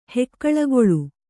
♪ hekkaḷagoḷu